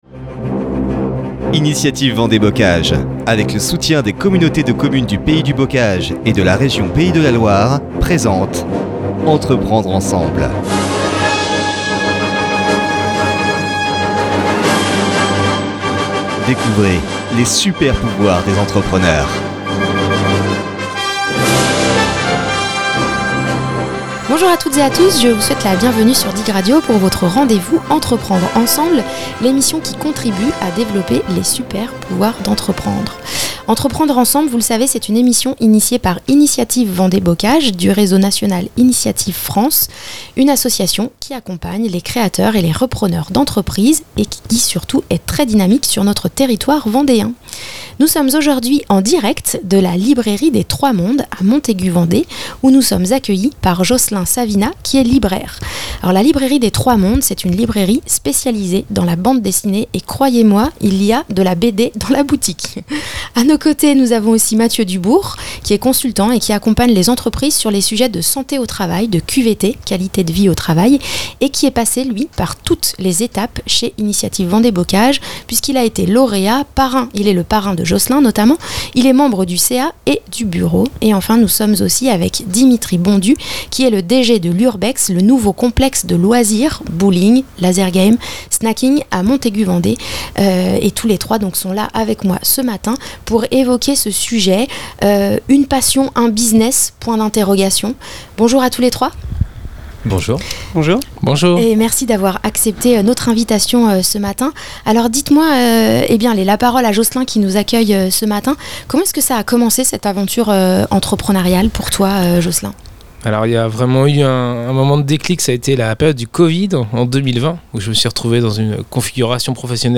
Podcast témoignage